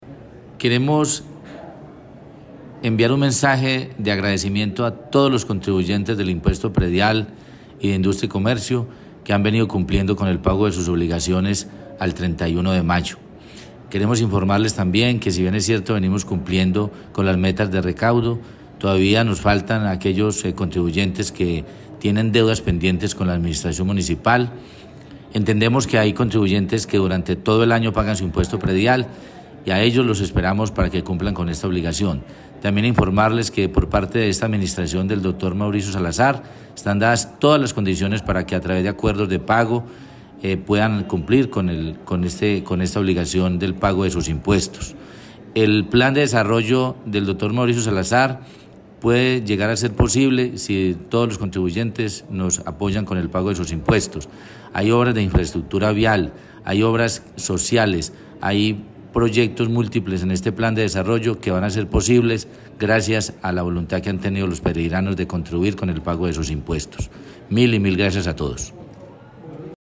El secretario de Hacienda Jorge Alexis Mejía Bermúdez, expresó su agradecimiento a los contribuyentes que hicieron este importante aporte e hizo un llamado a quienes faltan por ponerse al día.
JORGE-ALEXIS-MEJIA-BERMUDEZ-SECRETARIO-DE-HACIENDA-CIERRE-DE-RECAUDO.mp3